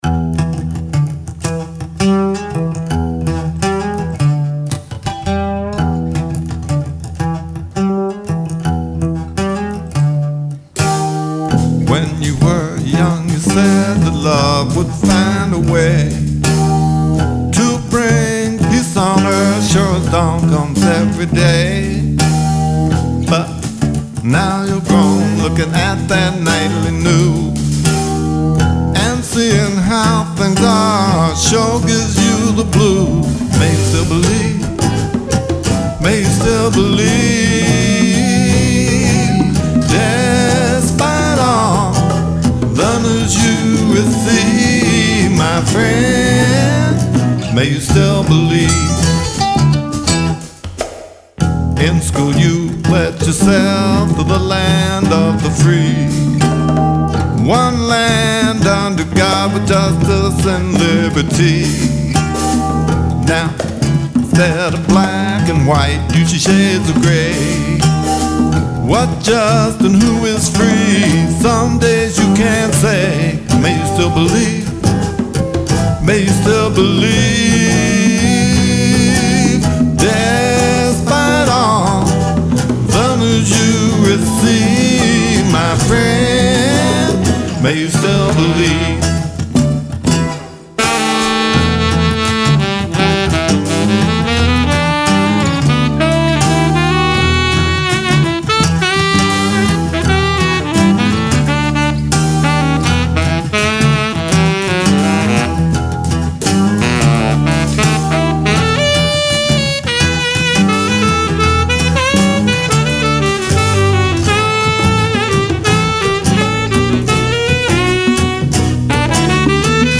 flute
bass
drums
sax
That’s me on guitar and vocals.